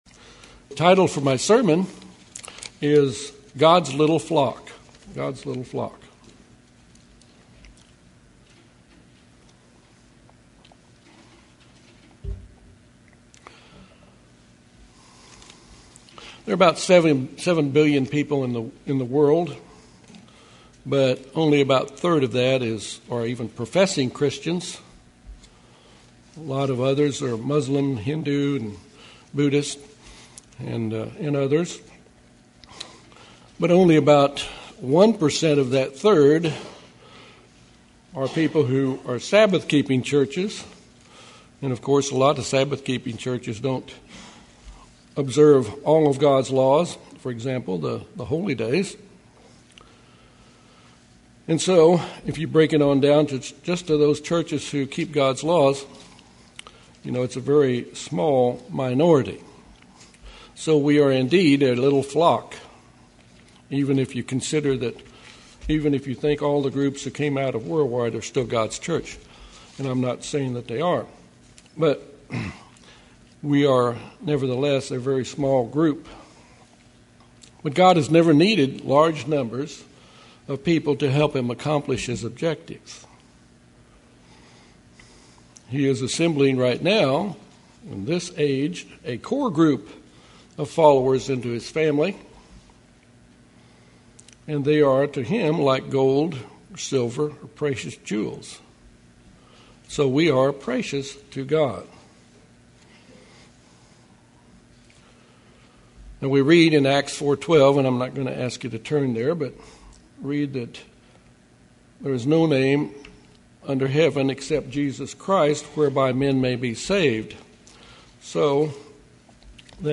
Sermons
Given in Little Rock, AR